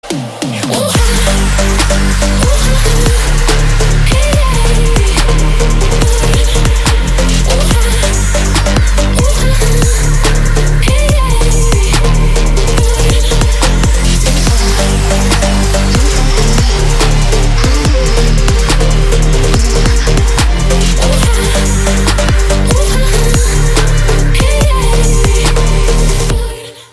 Remix, Android, Elektronisk musik